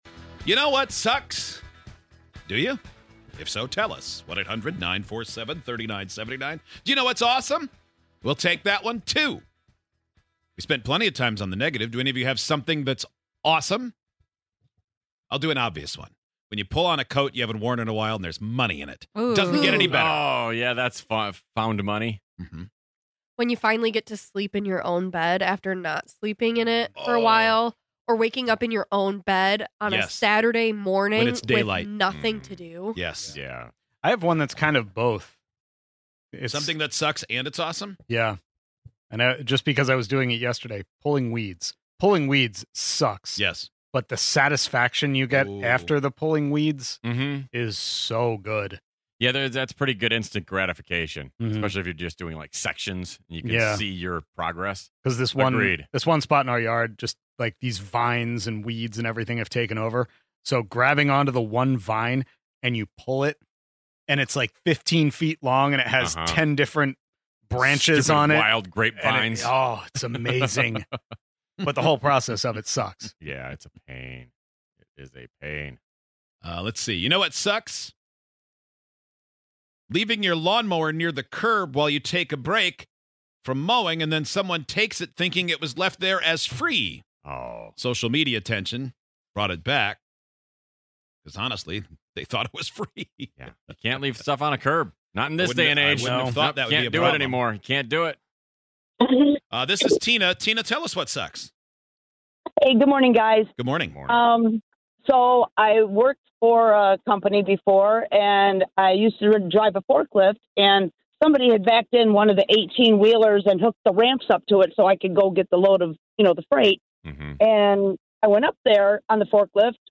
We don't really have an end goal with this one, it's just six idiots going on about things they hate and things they really really like.